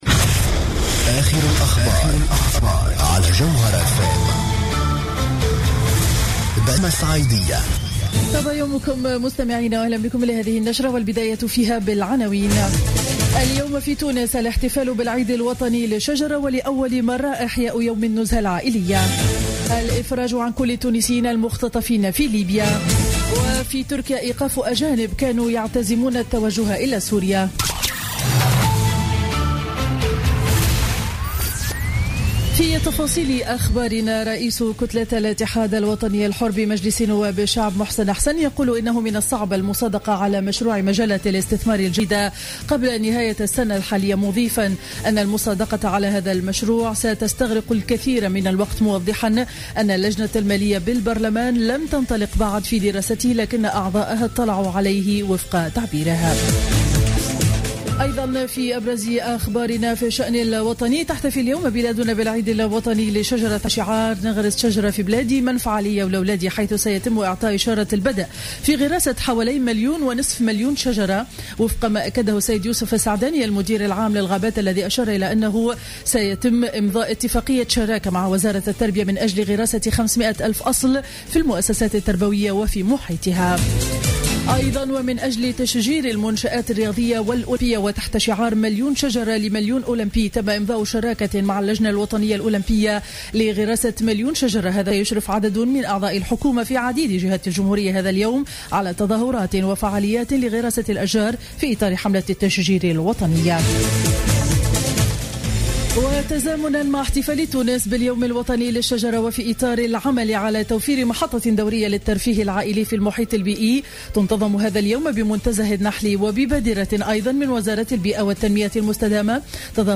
نشرة أخبار السابعة صباحا ليوم الأحد 8 نوفمبر 2015